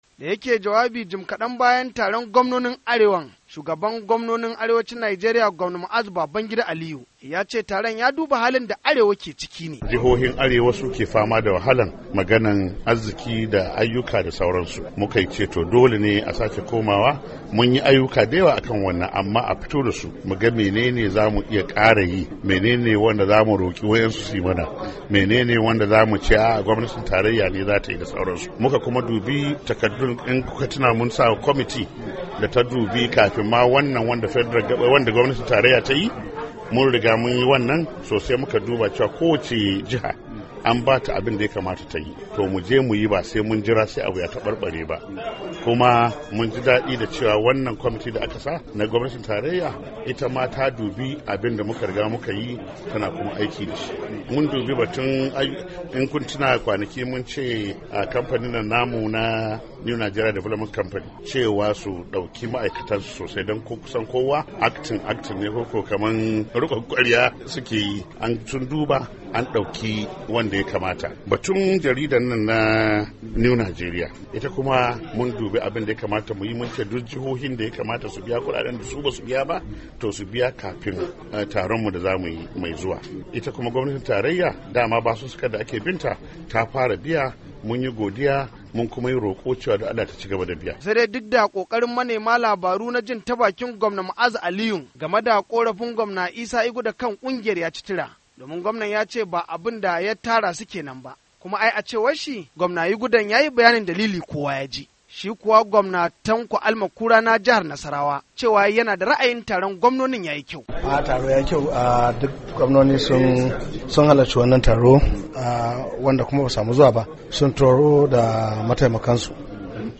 Rahoton Taron Kungiyar Gwamnonin Arewacin Najeriya - 3:07